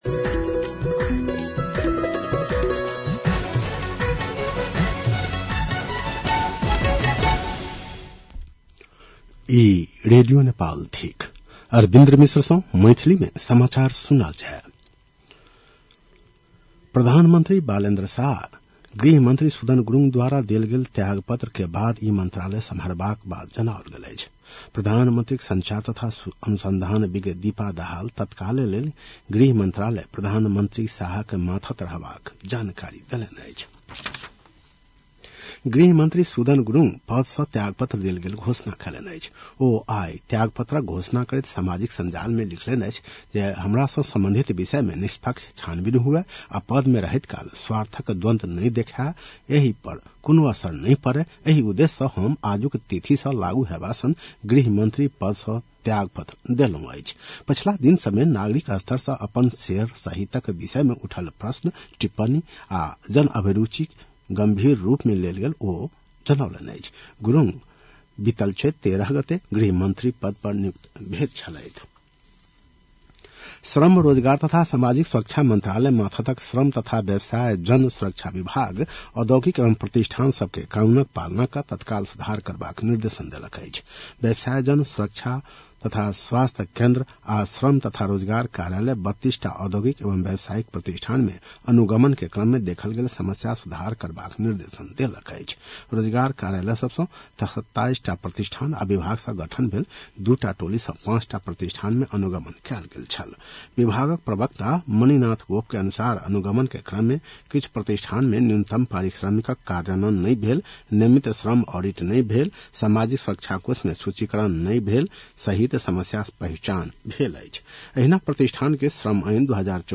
मैथिली भाषामा समाचार : ९ वैशाख , २०८३